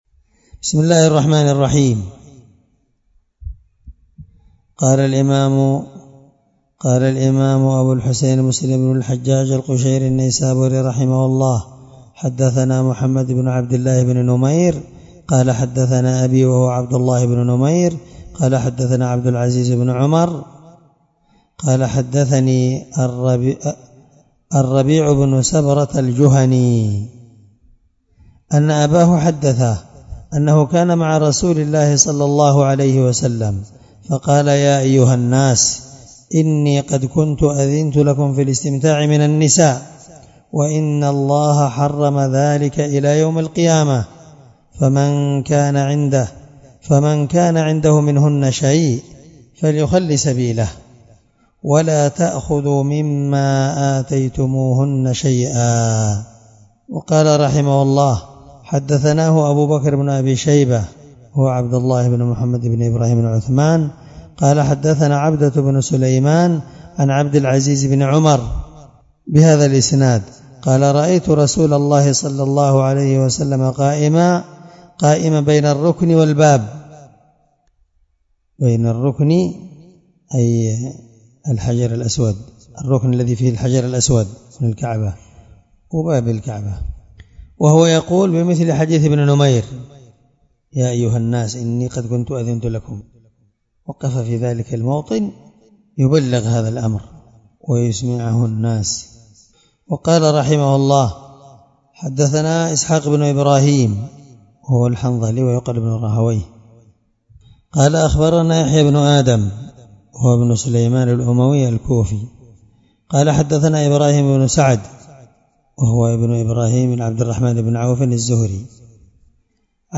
الدرس7من شرح كتاب النكاح حديث رقم(000) من صحيح مسلم